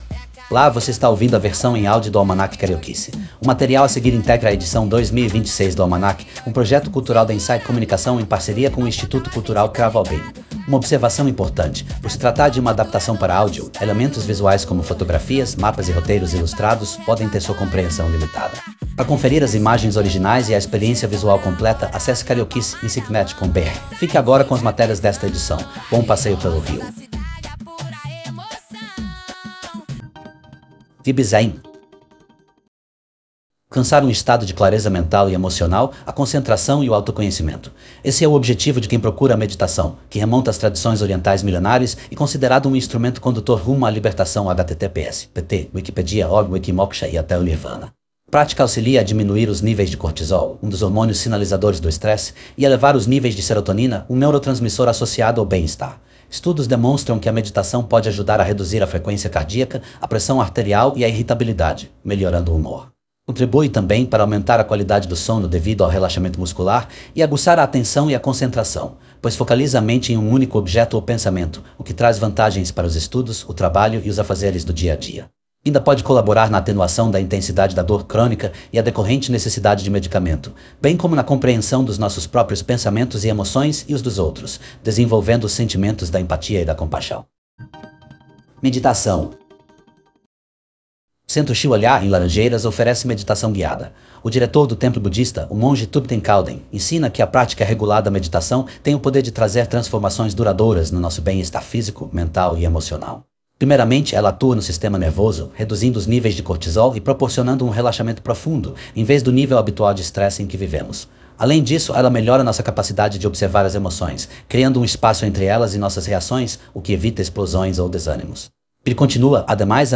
VIBE_ZEN_MEDITACAO_CENTRO_SHIWA_LHA.mp3